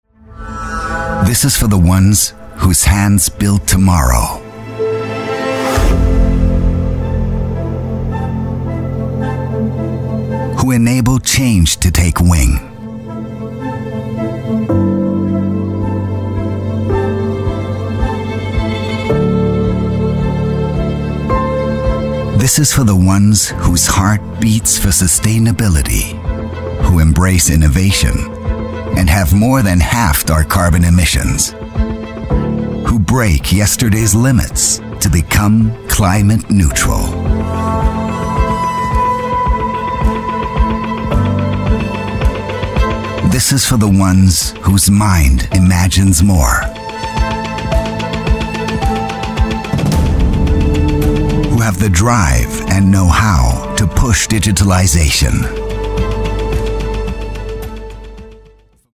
Dank seiner angenehmen sonoren Tonlage kommt er bei den Kunden immer hervorragend an.
Imagefilm